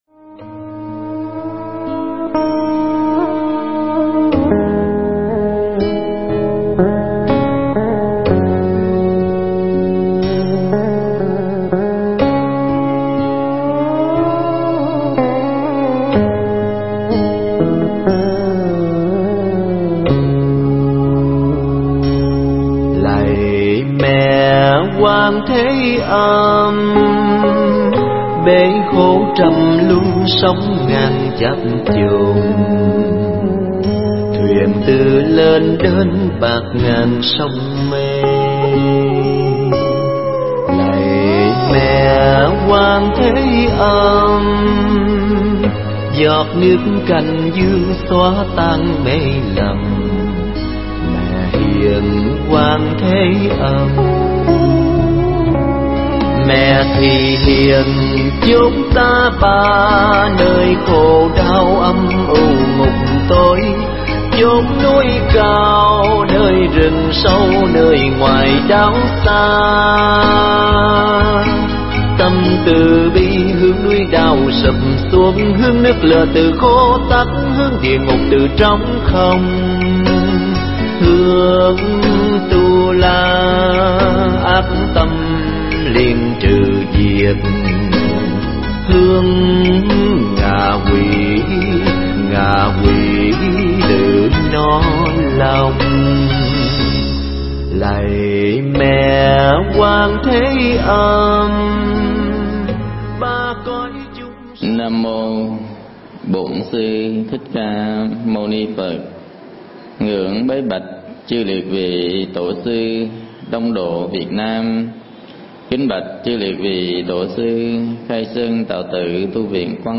Mp3 Pháp Thoại Đời Có Vay Có Trả
thuyết giảng ở Quan Âm Tu Viện – đường Trường Sa quận Phú Nhuận trong khóa tu An Lạc Một Ngày